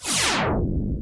laser.wav